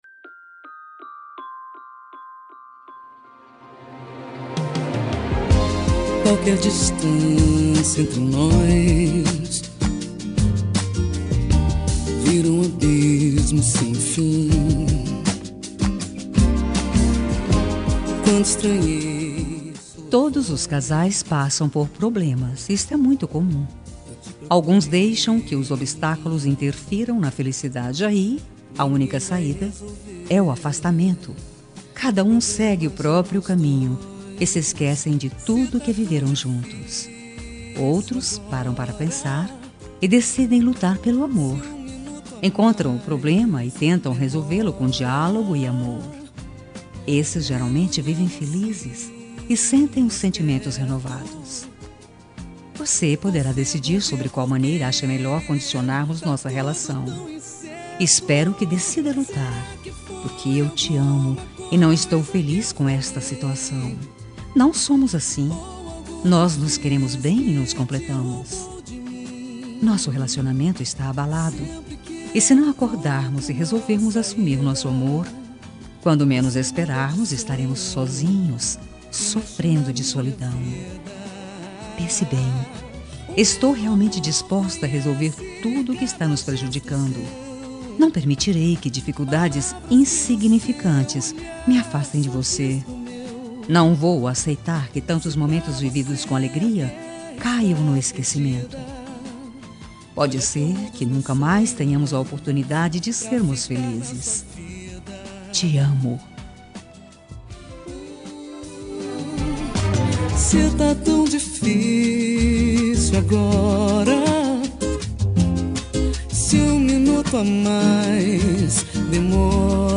Telemensagem Relacionamento Crise – Voz Feminina – Cód: 5436